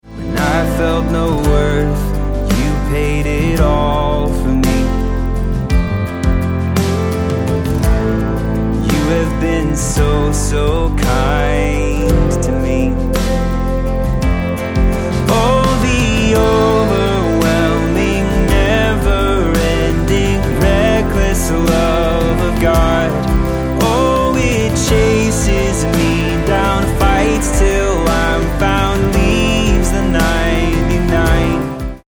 Am